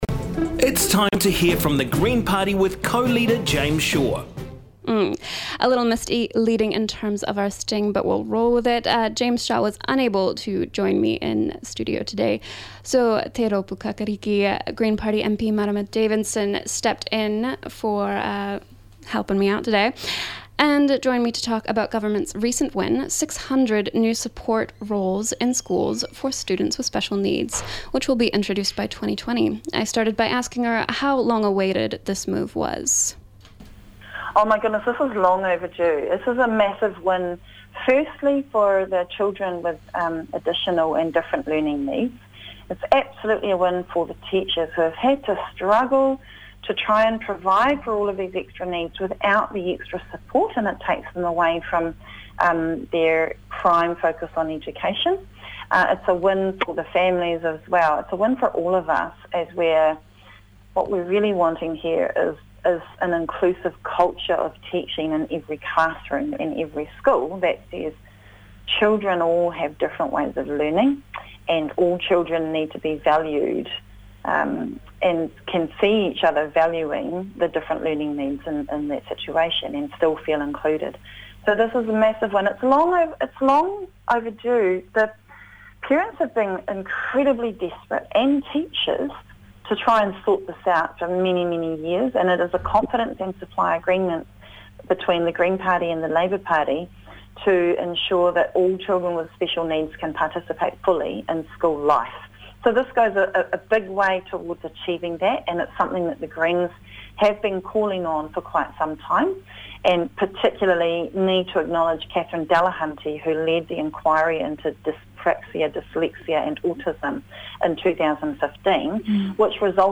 Green Party Co-Leader, Marama Davidson